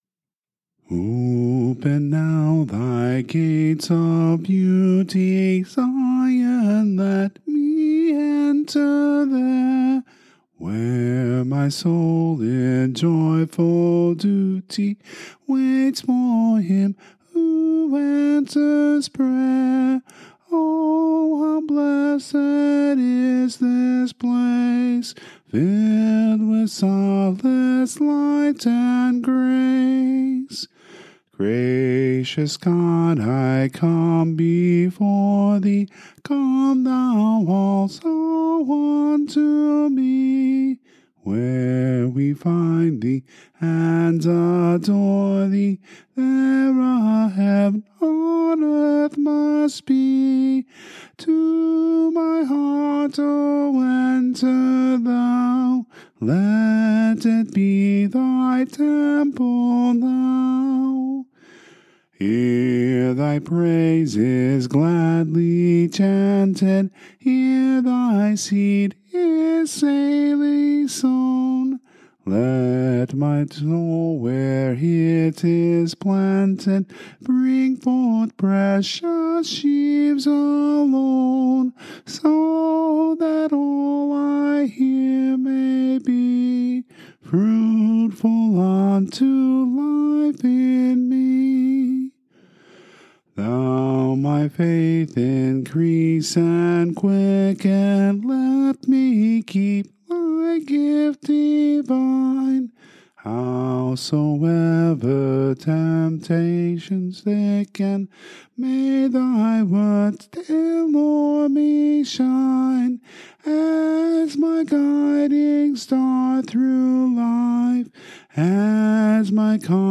Pentecost 6 Matins Service — Shepherd of the Hills Lutheran Church